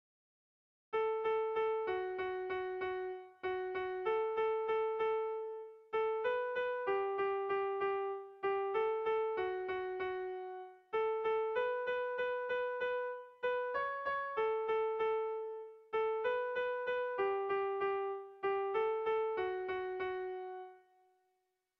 Bertso melodies - View details   To know more about this section
ABDB